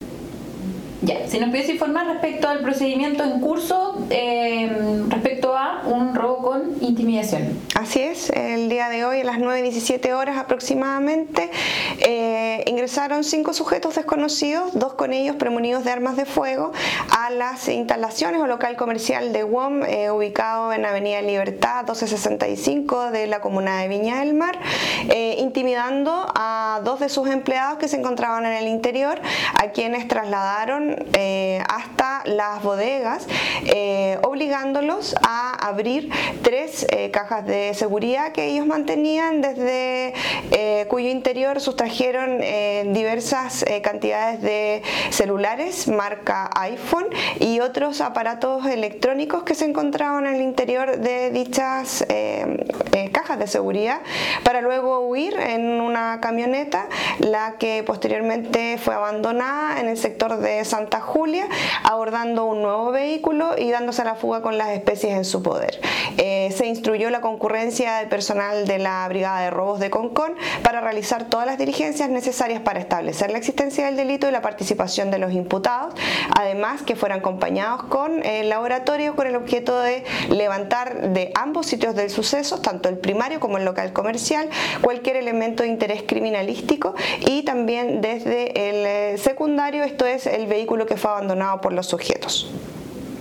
Así lo informó la Fiscal de Turno Regional de Instrucción y Flagrancia, Daniela Quevedo, quien  entregó detalles de este hecho:
CUNA-1-FISCAL-DANIELA-QUEVEDO-ROBO-WOM.mp3